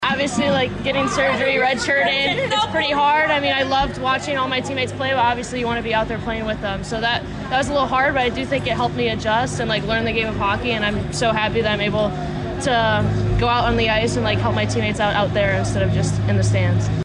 The Wisconsin native talked to Cave Sports about what it’s been like to be able to get on the ice this season…